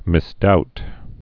(mĭs-dout)